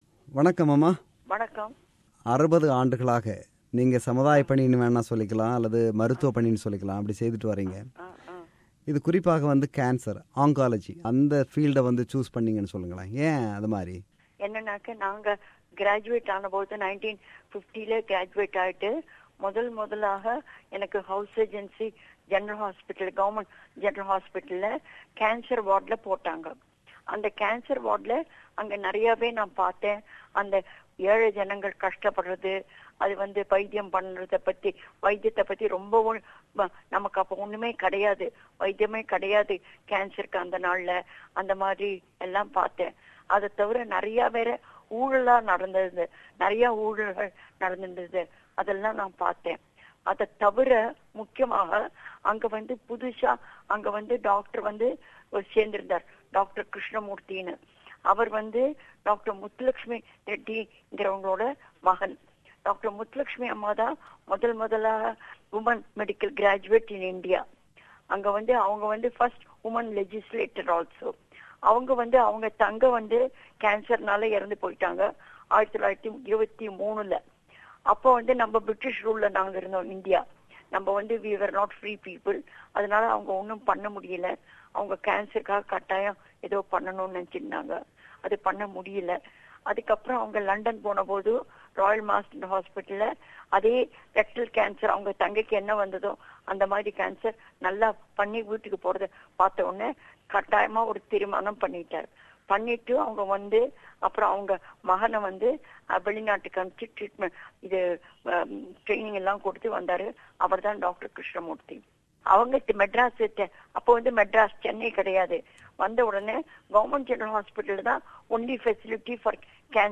கடந்த 2016ம் ஆண்டு அவர் நமக்கு வழங்கிய நேர்காணல் இது.